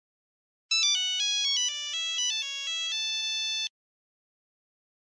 Nokia 3310 gute
nokia-3310-gute.mp3